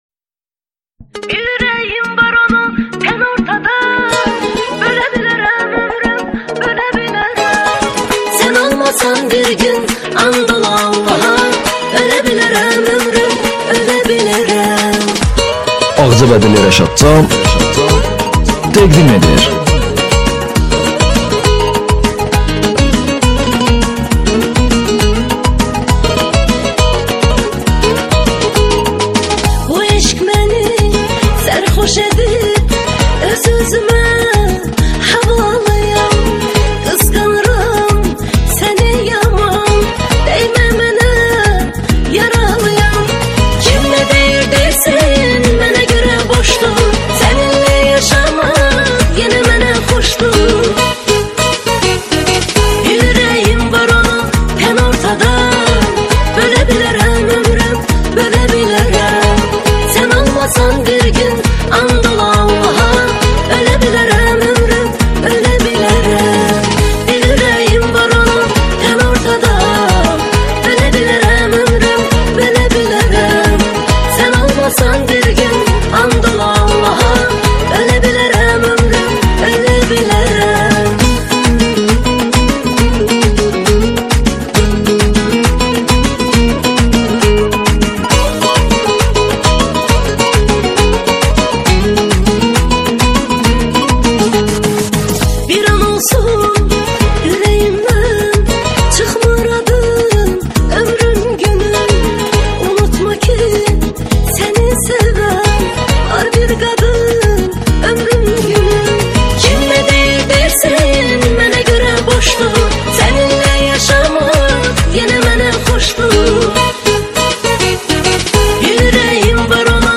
دانلود اهنگ ترکی غمگین